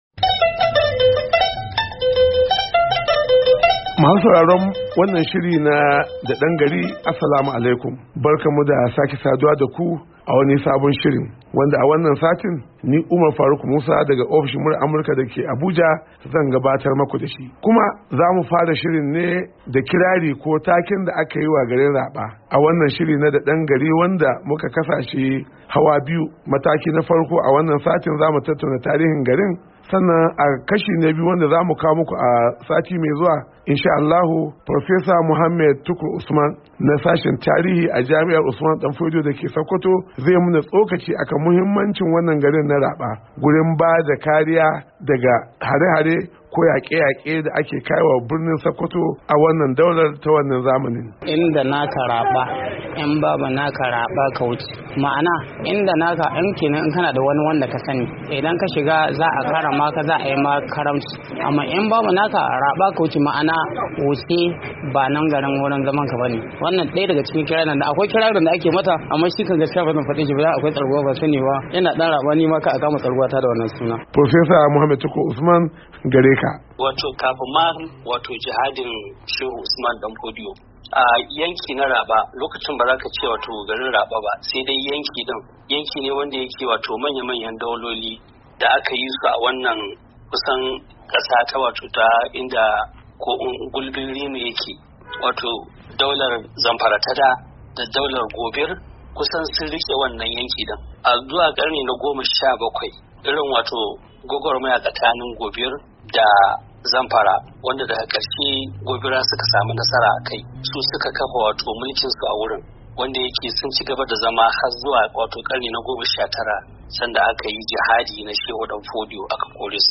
Shirin Da Dangari na wannan makon ya lalubo tarihin garin Rabah da ke jihar Sokoto. A wata hira